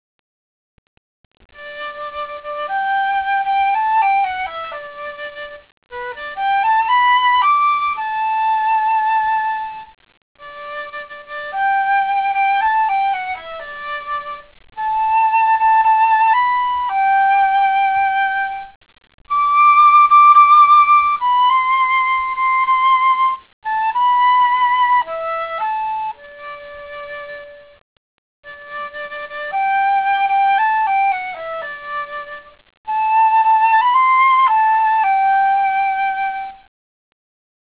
flûte traversière